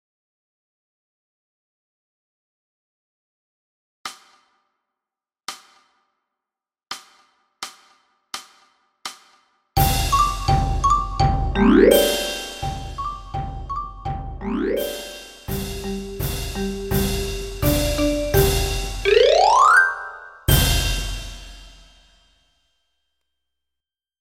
84 bpm